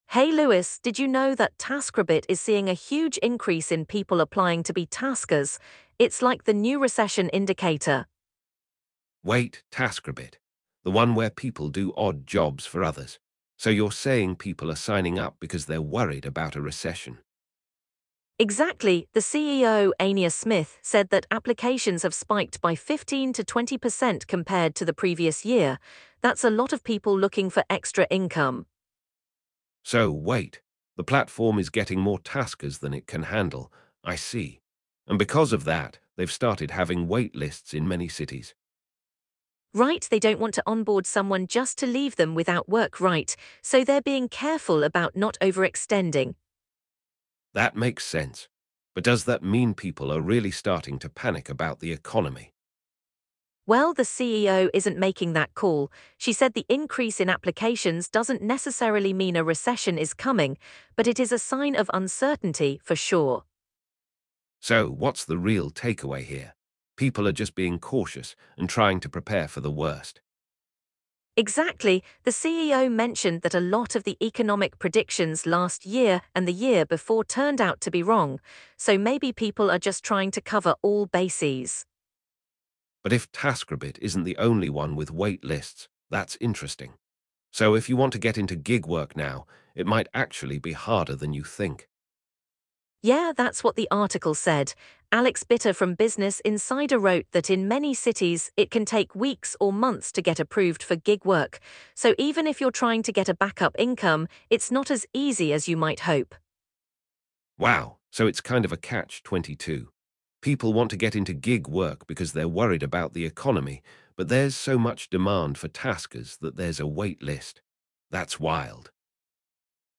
The conversation also delves into the broader gig economy and how other platforms are responding to the increased demand for gig workers.